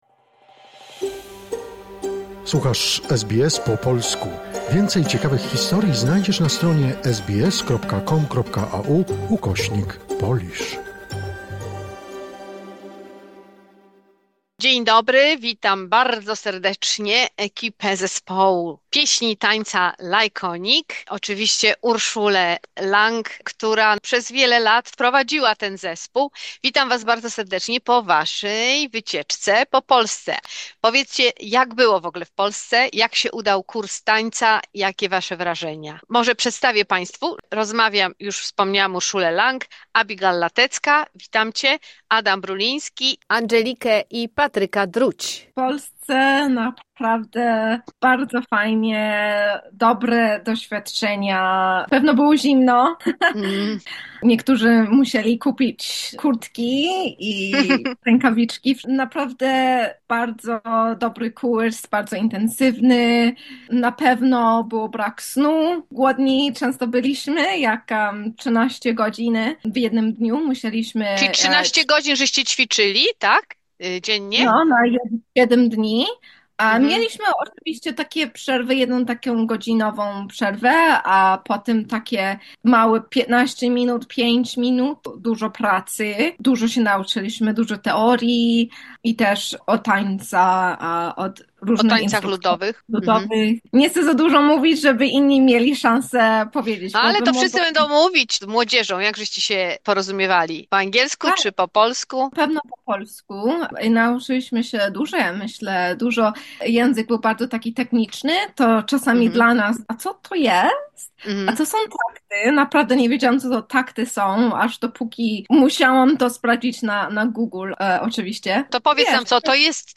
Rozmowa z członkami Zespołu Pieśni i Tańca "Lajkonik"